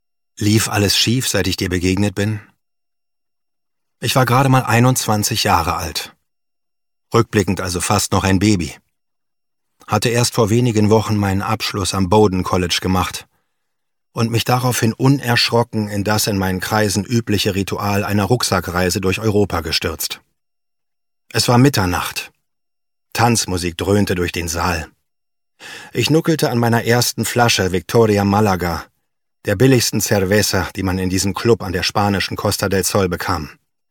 hoerbuch-in tiefster nacht